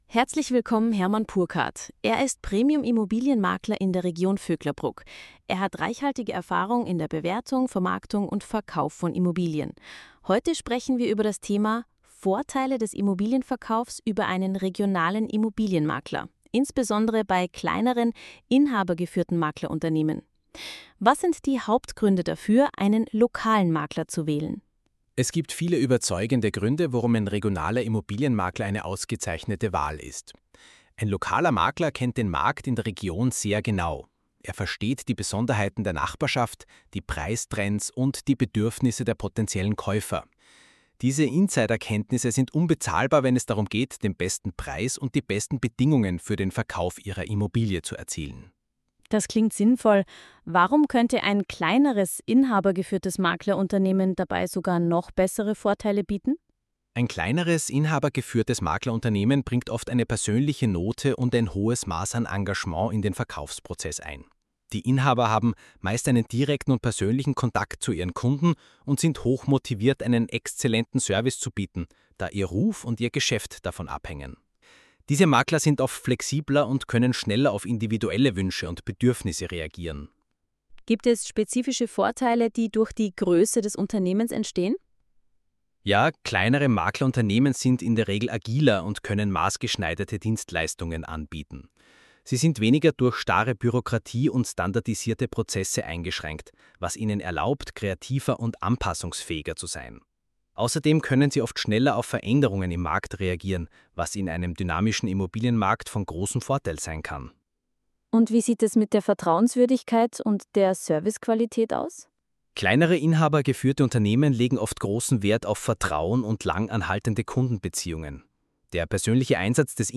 Interview immonews.mp3